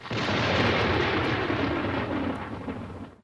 thunder1.ogg